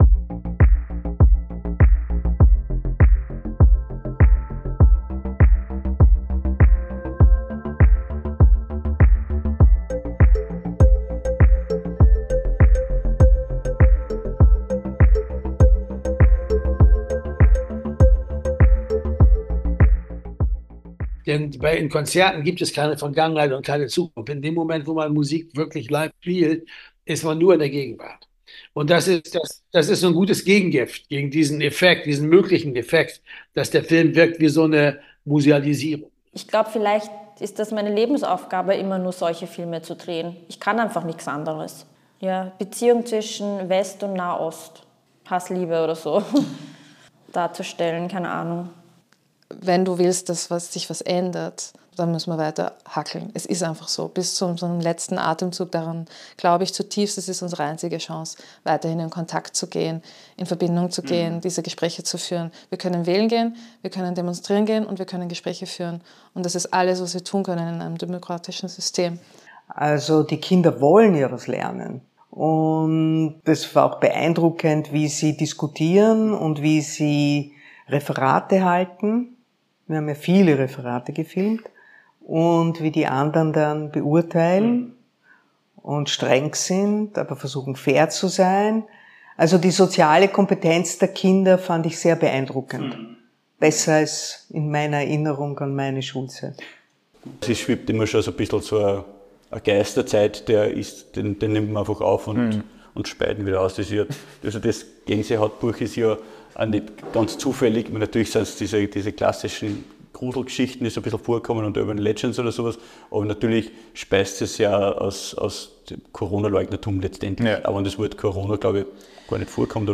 Was soll das alles? - Interview-Podcast (Trailer)